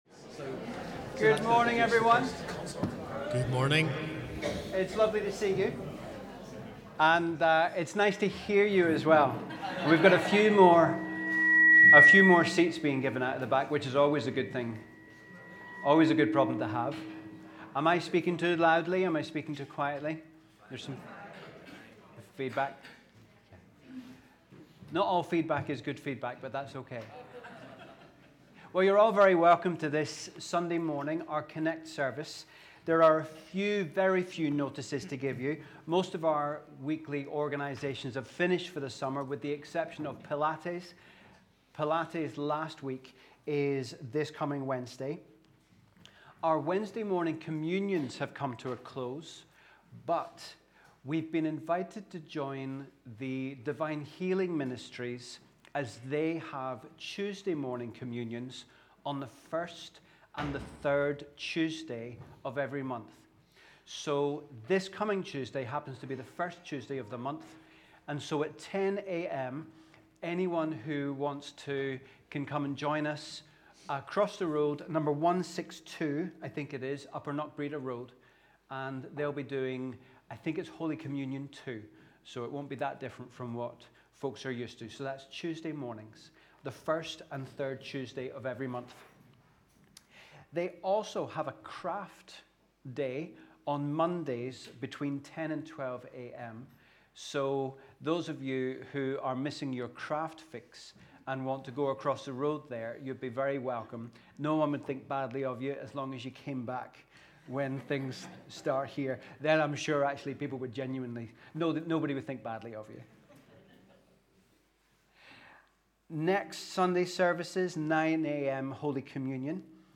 We warmly welcome you to our CONNEC+ service as we worship together on the 4th Sunday after Trinity.